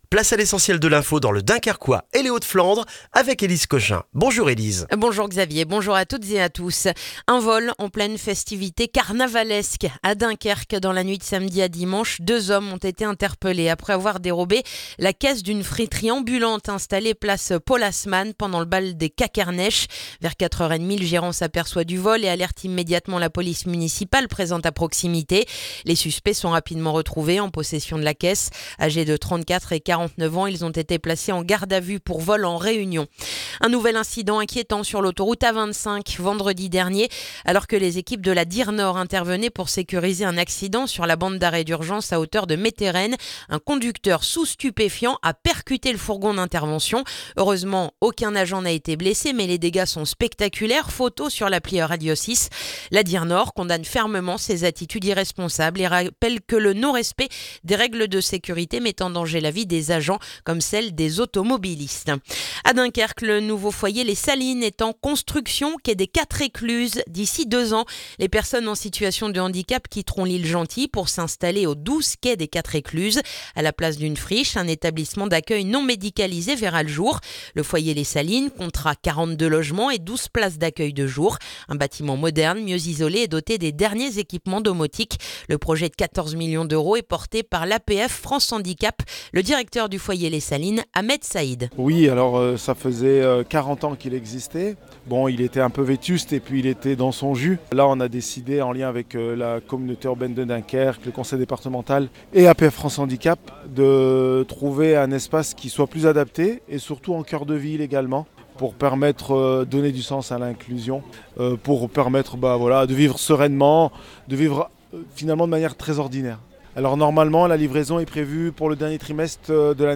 Le journal du mardi 3 février dans le dunkerquois